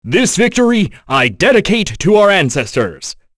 Dakaris-Vox_Victory_b.wav